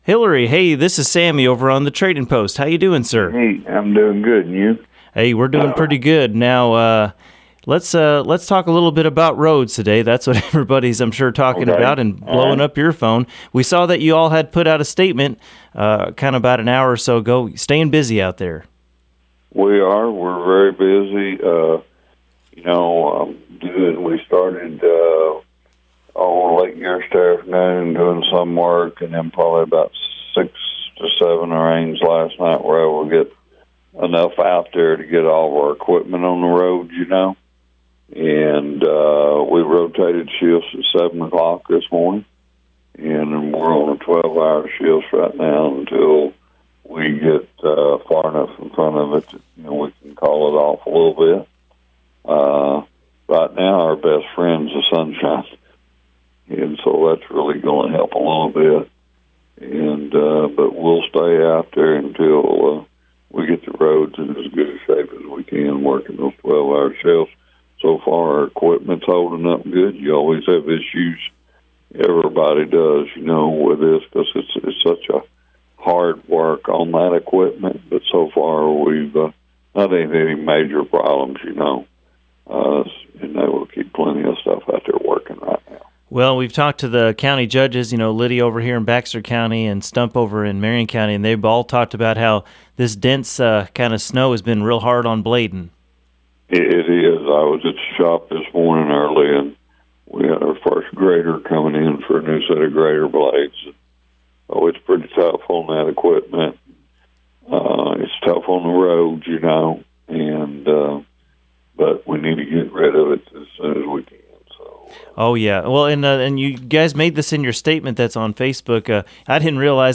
Mountain Home Mayor Hillrey Adams called in for today's episode of the Trading Post on Classic Hits 101.7 and KTLO AM 1240.